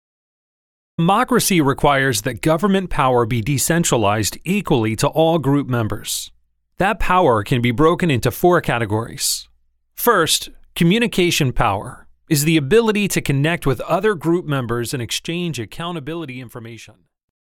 Our audiobook publishing services provide professional narration, sound engineering, and production to create a high-quality listening experience that reflects your voice and message.